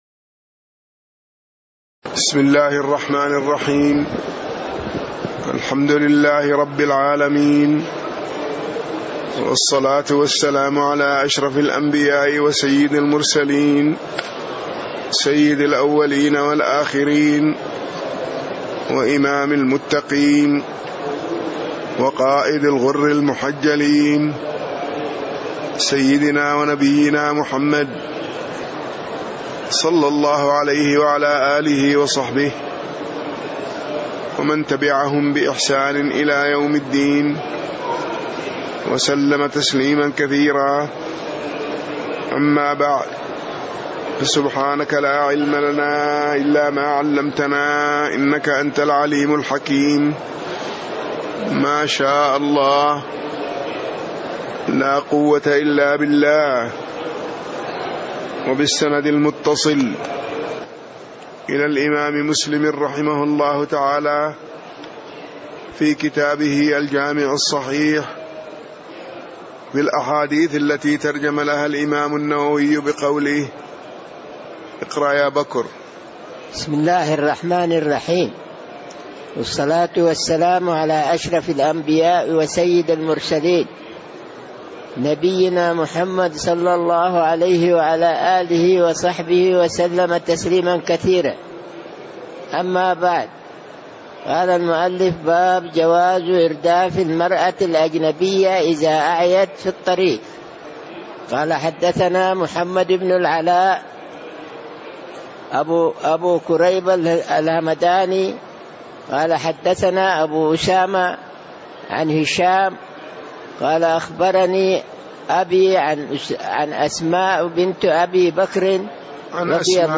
تاريخ النشر ٨ محرم ١٤٣٧ هـ المكان: المسجد النبوي الشيخ